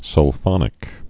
(sŭl-fŏnĭk)